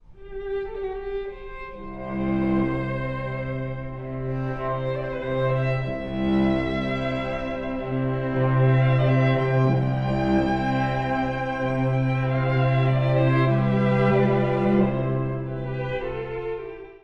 第3楽章…動きのあるカンタービレ
“Andante cantabile”美しい緩徐楽章です。
最初こそ穏やかですが、徐々に中低弦主体の3連符で楽章全体に動きが出てきます。
一方、カンタービレなのにときどきぽつりと途絶えることもある…不思議な楽章です。